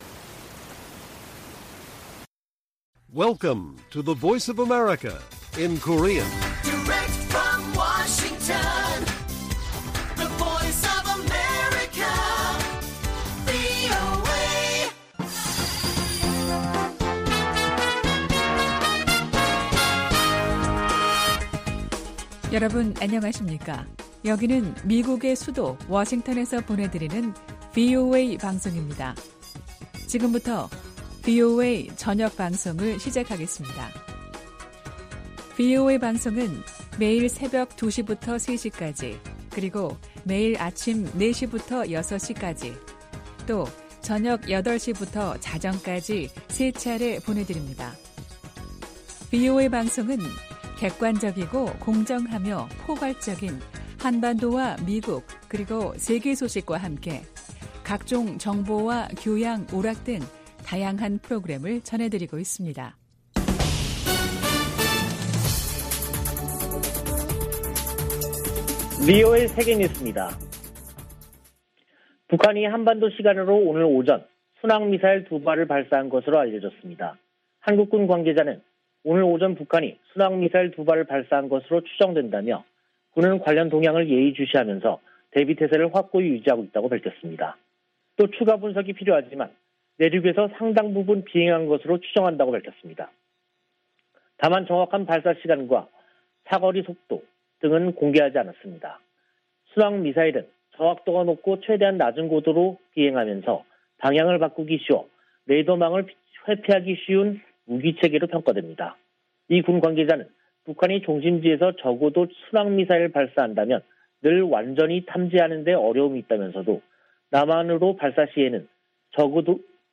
VOA 한국어 간판 뉴스 프로그램 '뉴스 투데이', 2022년 1월 25일 1부 방송입니다. 북한이 순항미사일로 추정되는 발사체 2발을 쏜 것으로 전해졌습니다.